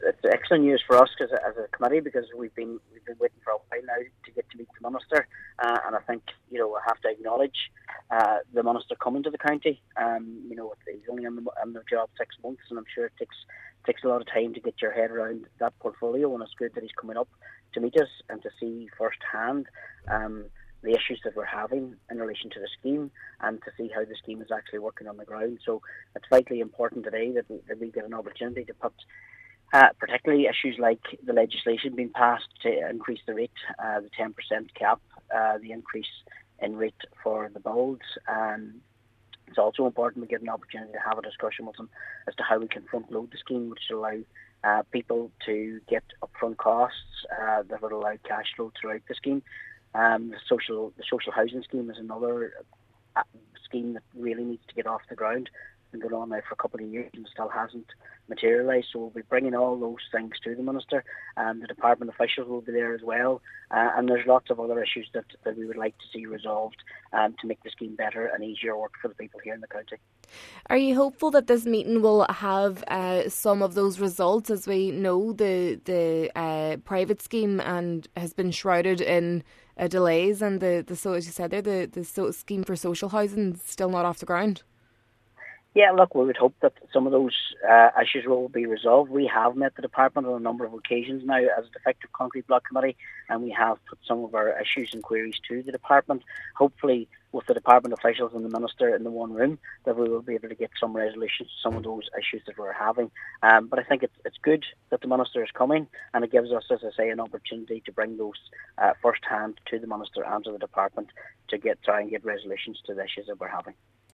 Cllr Martin McDermott says getting the housing officials and Minister James Browne in one room will be significant: